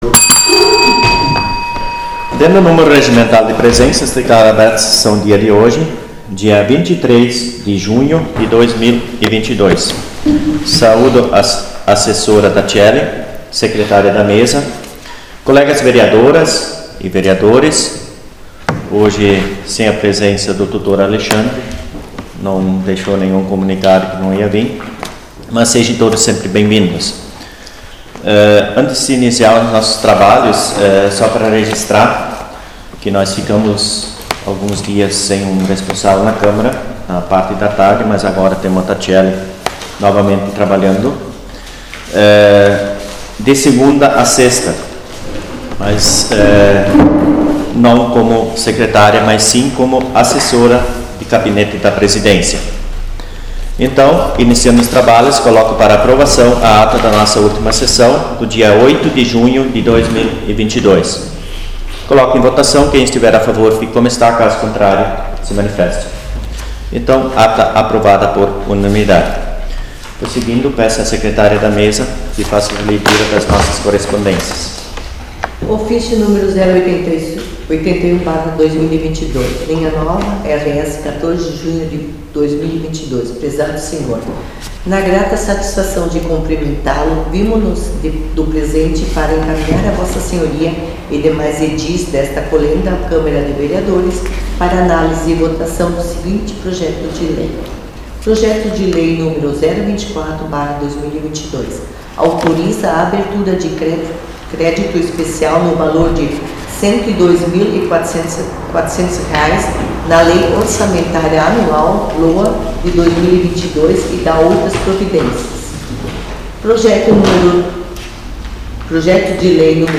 Dia 23 de junho de 2022 ocorreu a 11ª Sessão Ordinária da Câmara de Vereadores.